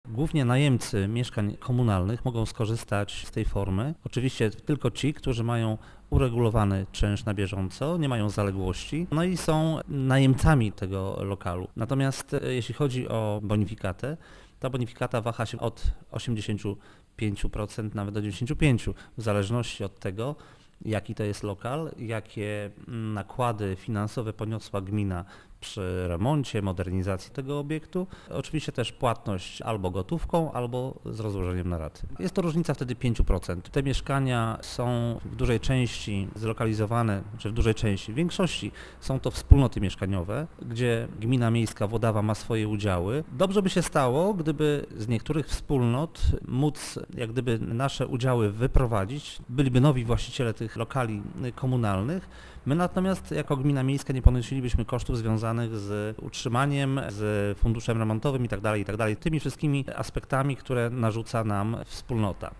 Do kogo jest kierowana propozycja i dlaczego miasto z nią występuje - wyjaśnia burmistrz Jerzy Wrzesień: